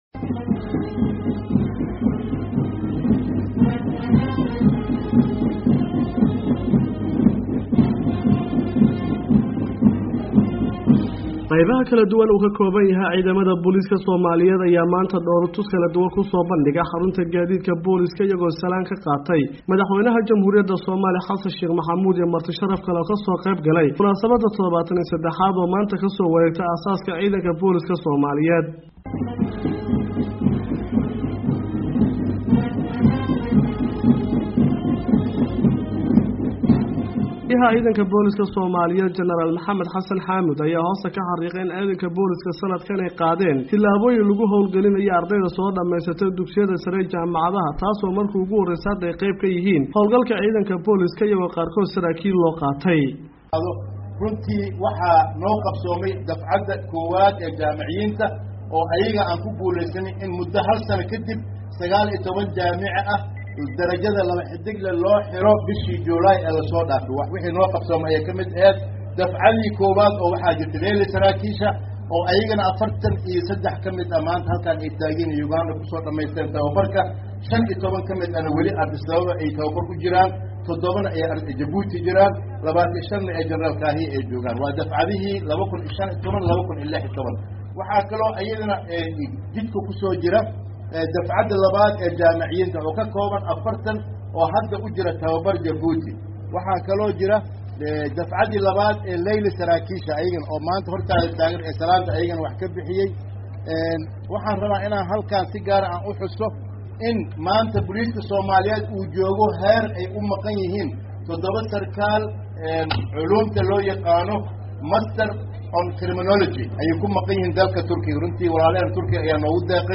Munaasabad lagu xusay aas aaska ciidanka Boliiska Soomaaliyeed oo ay ka soo qaybgaleen Madaxweynaha Soomaliya Xassan Sheekh Maxamuud iyo taliyaasha ciidanka Soomaaliyeed, ayaa maanta lagu qabtay magaalada Muqdisho.
Warbixin: Aas aaska ciidamada boliiska